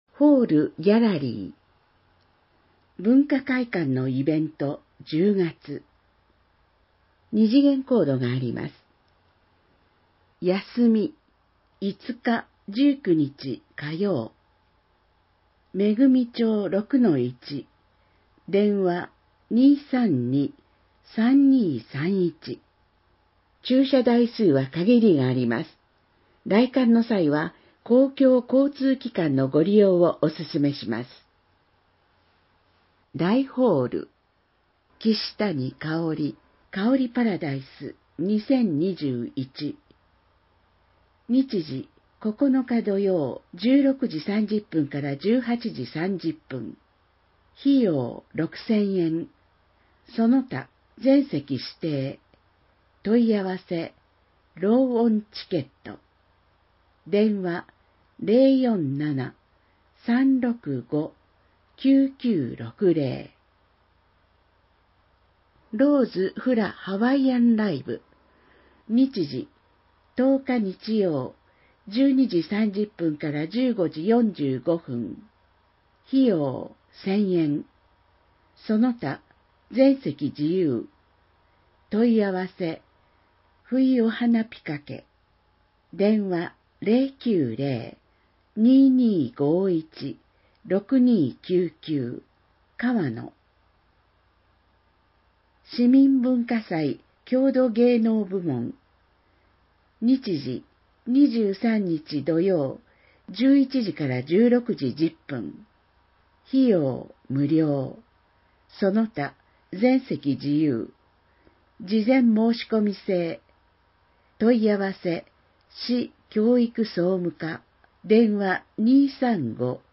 広報えびな 令和3年9月15日号（電子ブック） （外部リンク） PDF・音声版 ※音声版は、音声訳ボランティア「矢ぐるまの会」の協力により、同会が視覚障がい者の方のために作成したものを登載しています。